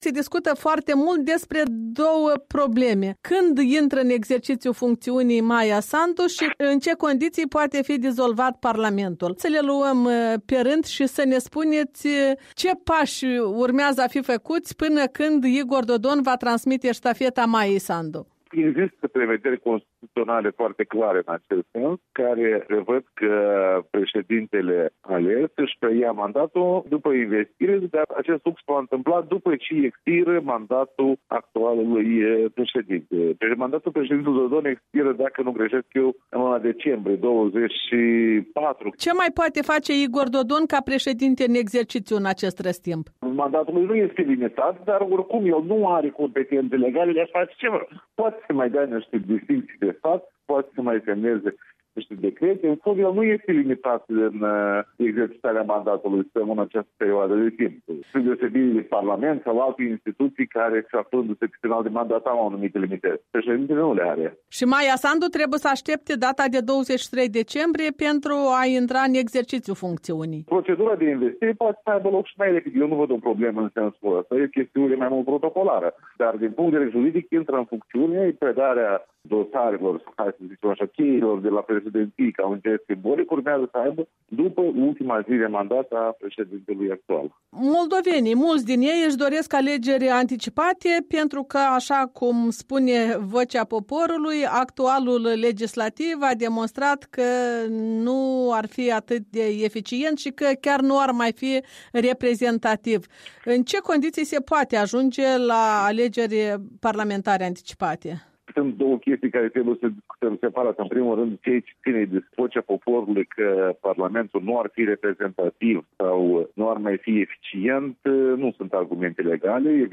Interviu cu Alexandru Tănase